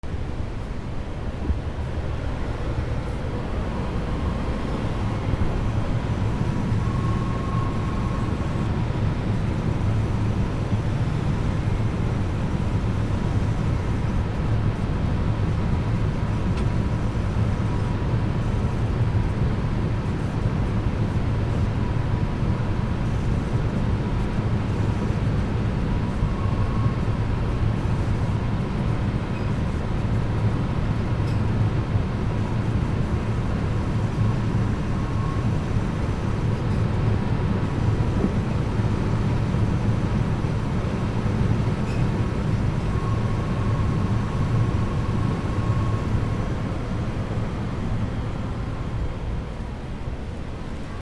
冷蔵ケース スーパー
/ K｜フォーリー(開閉) / K20 ｜収納などの扉